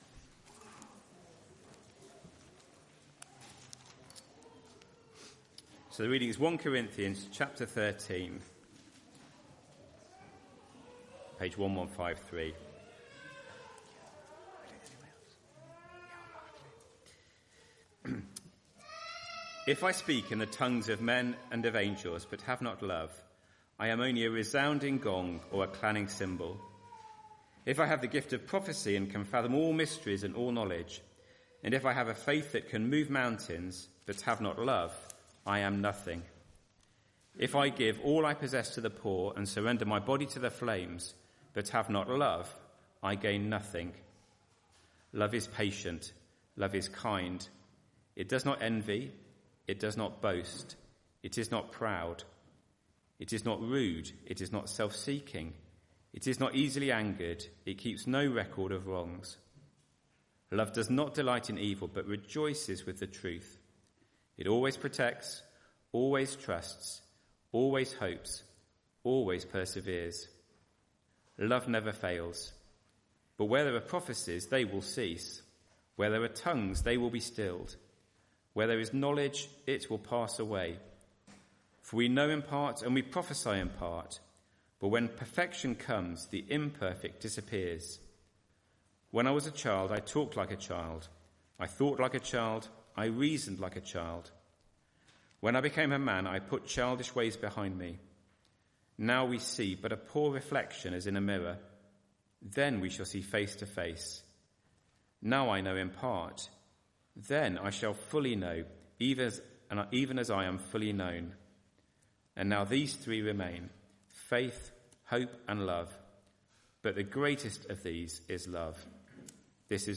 Media for Arborfield Morning Service on Sun 08th Jun 2025 10:00
Sermon